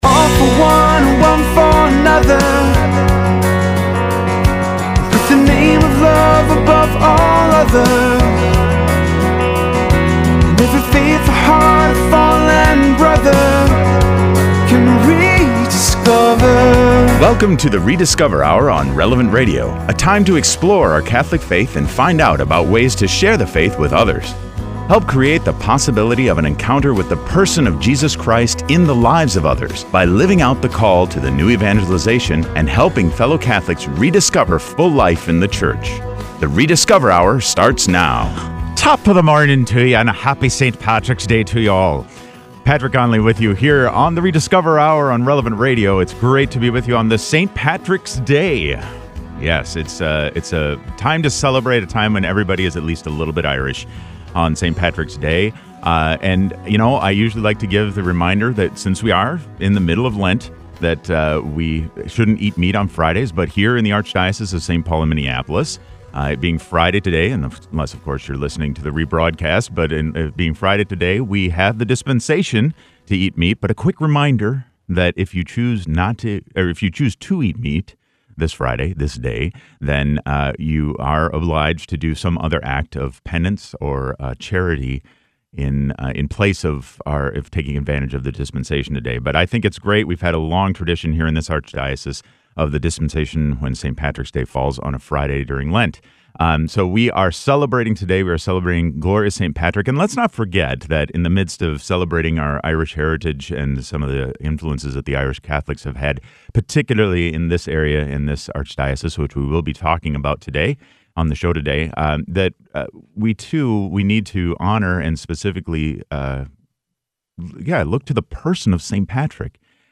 Al final del programa, tendremos a un invitado especial, Arzobispo Flynn !